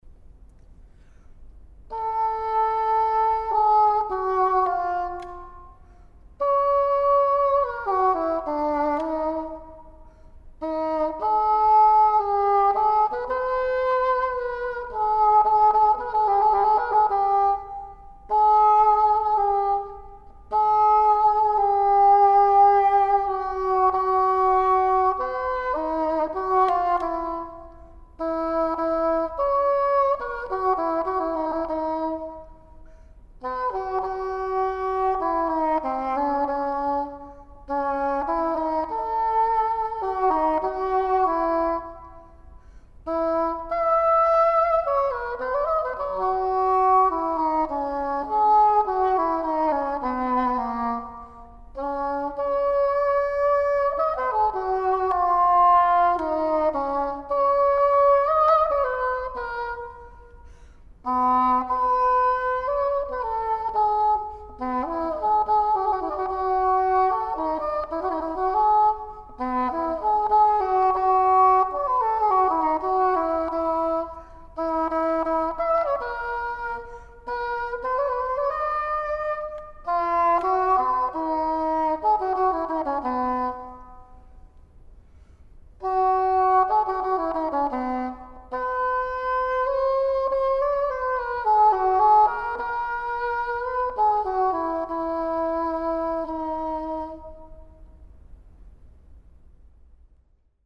Cor Anglais